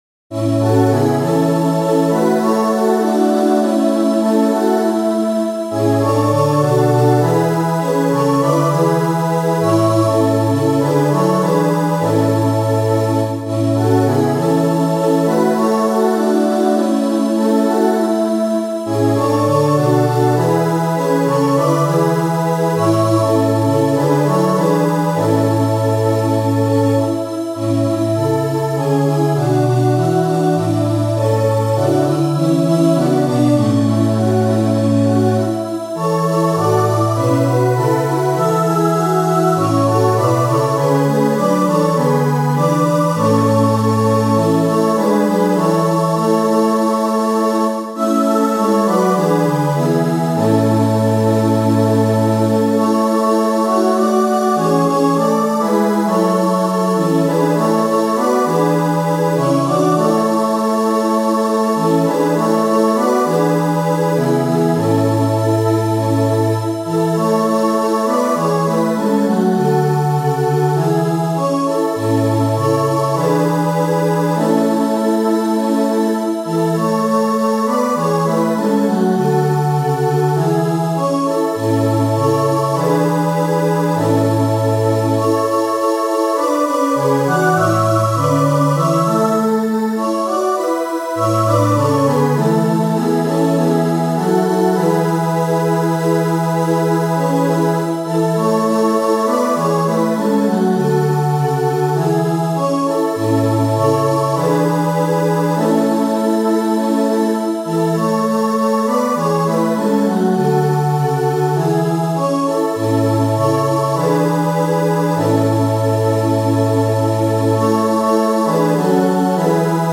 • Catégorie : Chants d’Offertoire